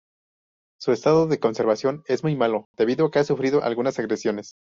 Pronounced as (IPA) /suˈfɾido/